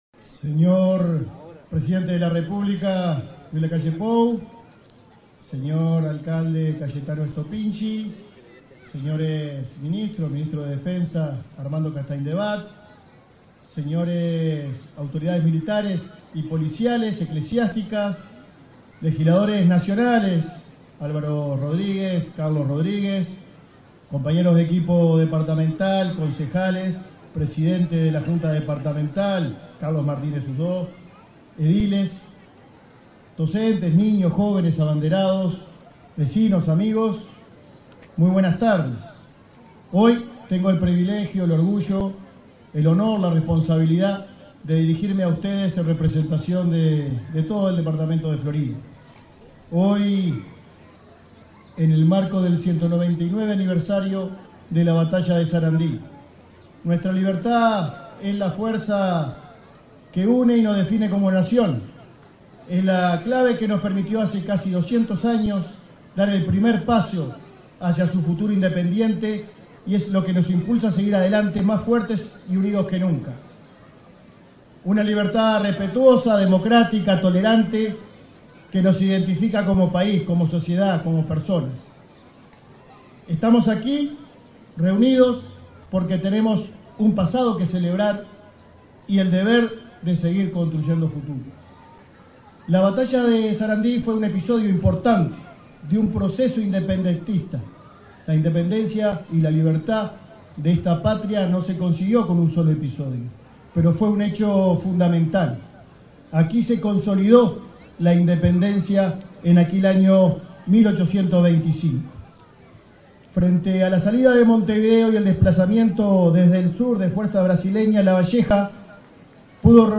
Palabras del intendente de Florida, Guillermo López
En el marco del acto conmemorativo central del 199.° aniversario de la Batalla de Sarandí, este 12 de octubre, se expresó el intendente de Florida,